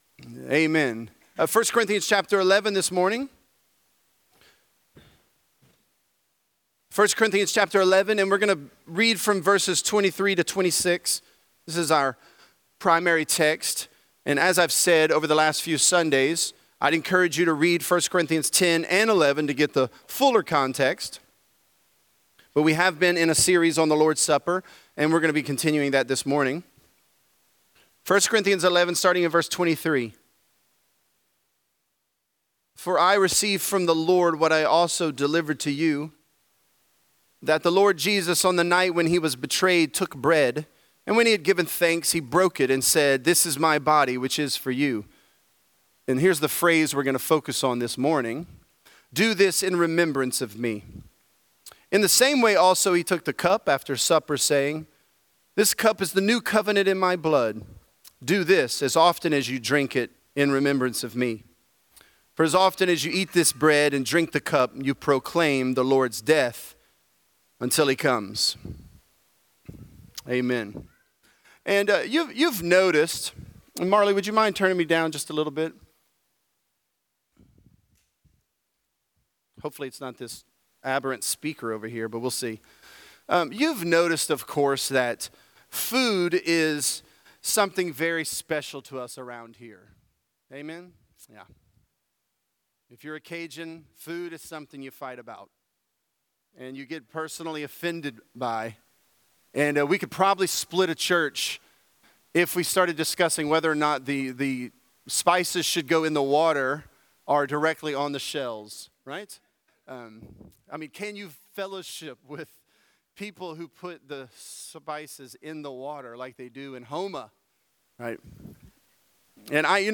The Lord's Supper: Remembrance of Me | Lafayette - Sermon (1 Corinthians 11)